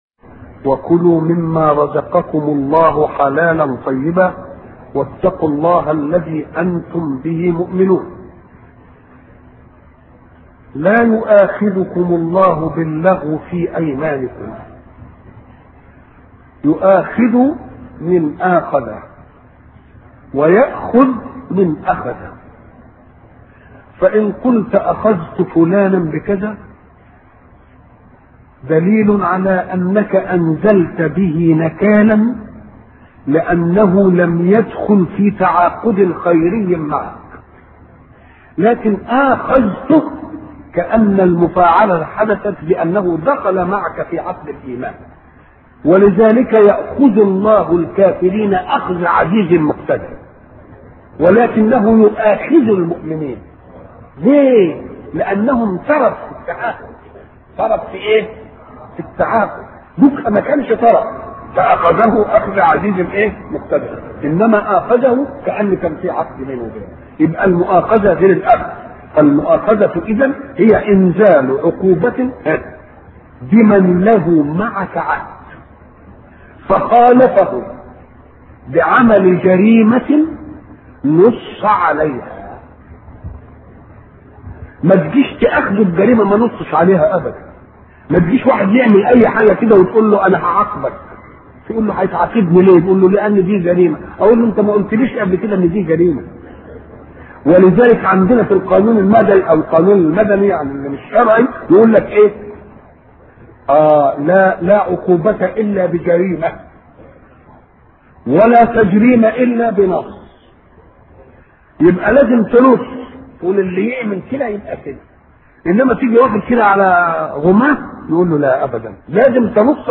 أرشيف الإسلام - ~ أرشيف صوتي لدروس وخطب ومحاضرات الشيخ محمد متولي الشعراوي